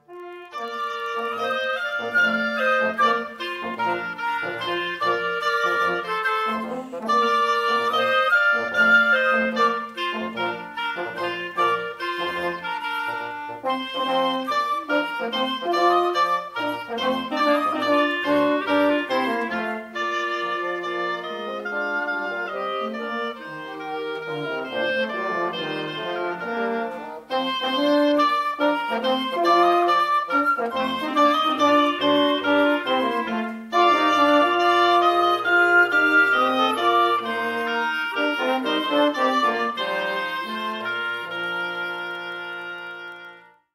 Popular prelude and ceremony music for weddings